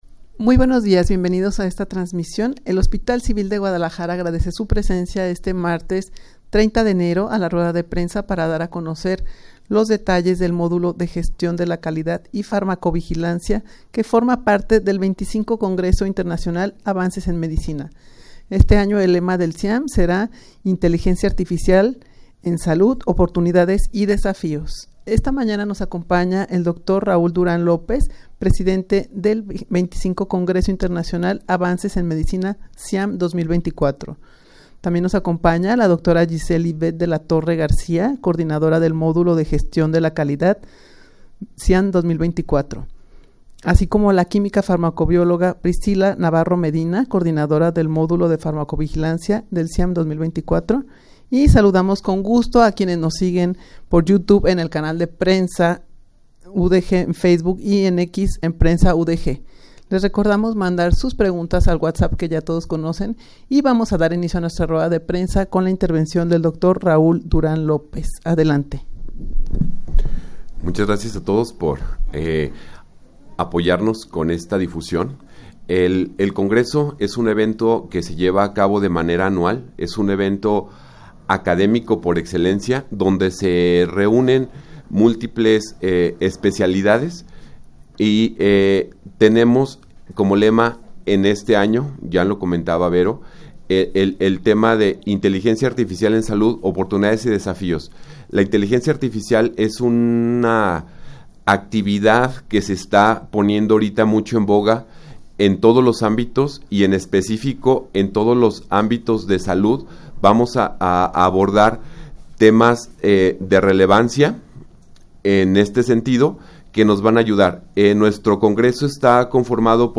Audio de la Rueda de Prensa
rueda-de-prensa-para-dar-a-conocer-los-detalles-del-modulo-de-gestion-de-la-calidad-y-farmacovigilancia.mp3